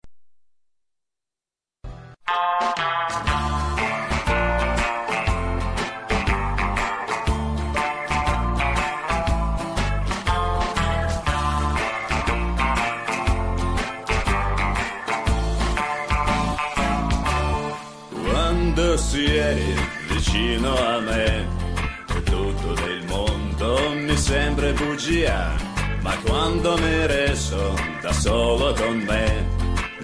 Песня на итальянском, но кажется что это кто-то из российских исполнителей.